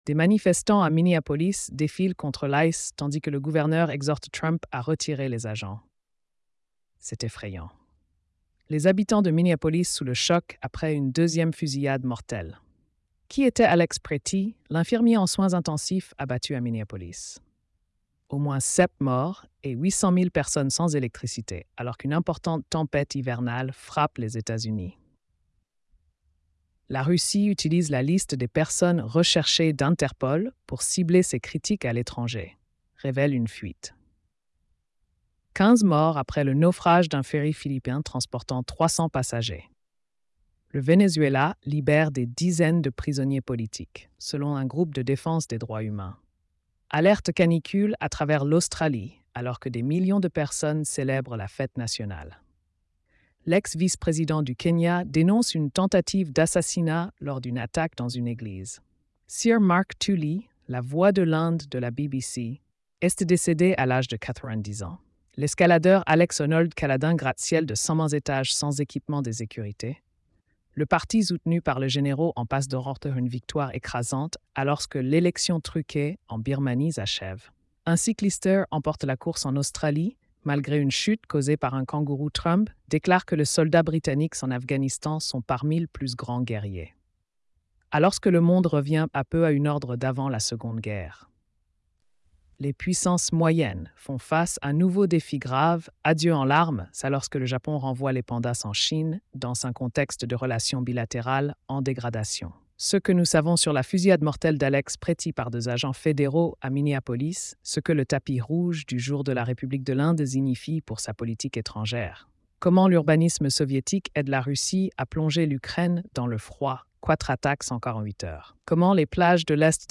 🎧 Résumé des nouvelles quotidiennes. |